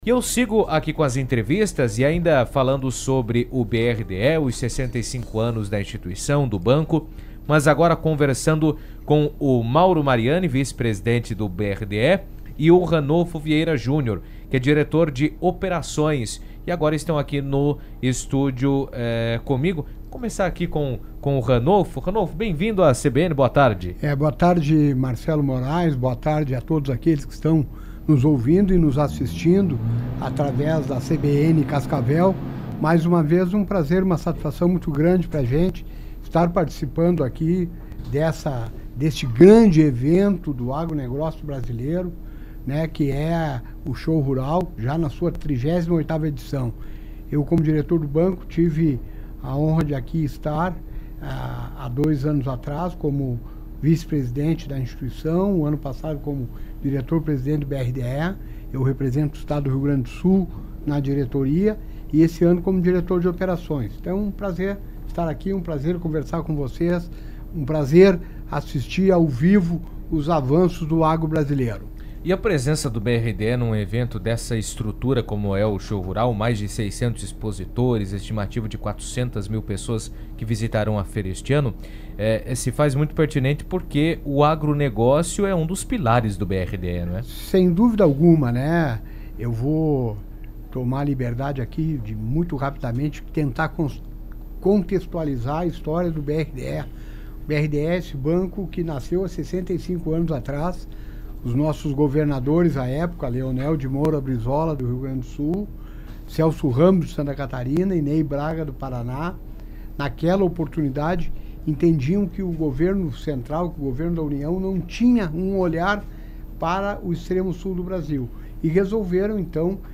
estiveram no estúdio da CBN durante a 38ª edição do Show Rural Coopavel e fizeram um balanço dos 65 anos do BRDE, destacando o papel do banco no desenvolvimento regional.